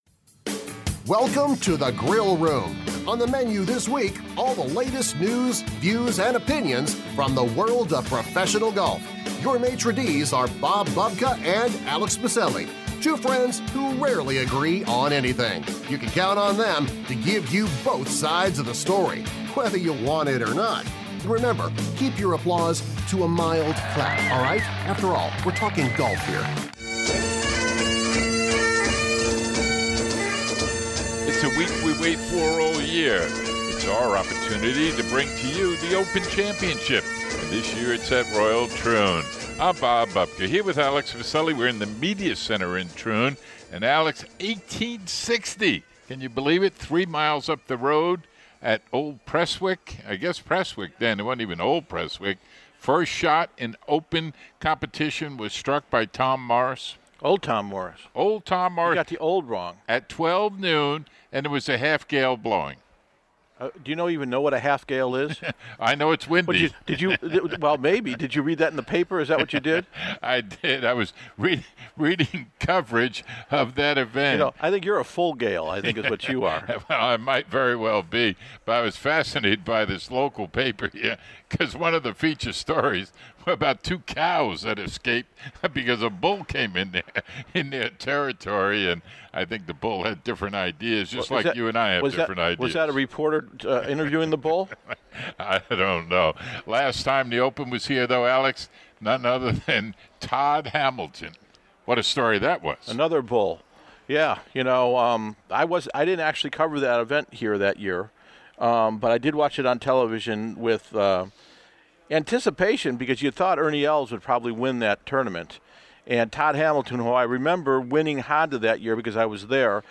We hear from several players - McIlroy, Spieth, Zach Johnson, Day and Danny Willett. McIlroy shares his forthright thoughts on the Olympics to the surprise of many.